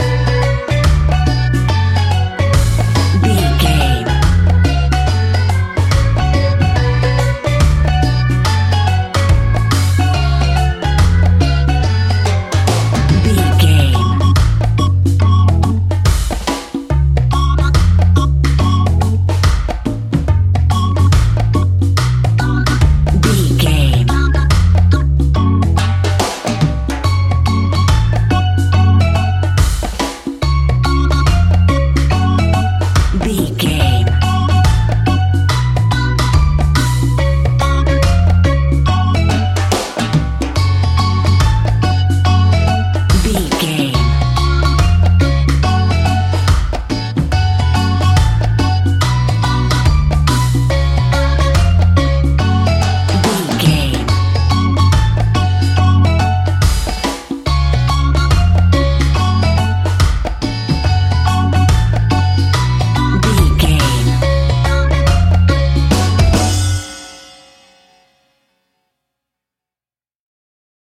Aeolian/Minor
steelpan
drums
bass
brass
guitar